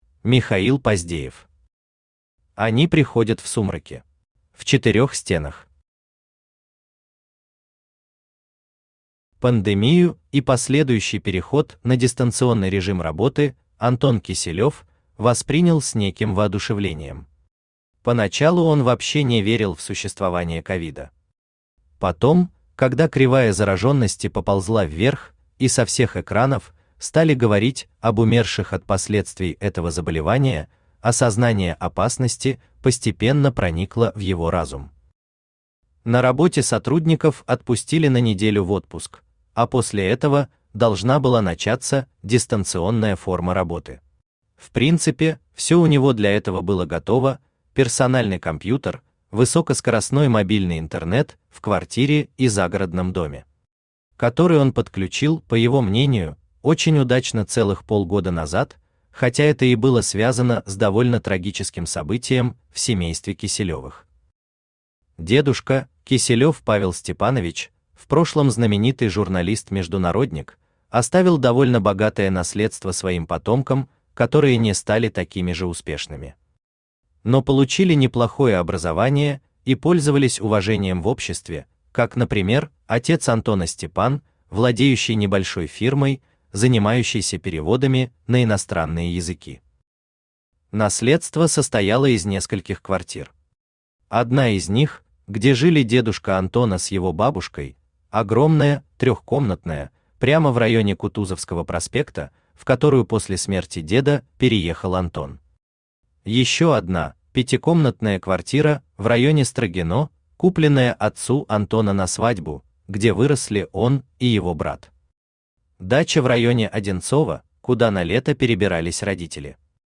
Аудиокнига Они приходят в сумраке | Библиотека аудиокниг
Aудиокнига Они приходят в сумраке Автор Михаил Викторович Поздеев Читает аудиокнигу Авточтец ЛитРес.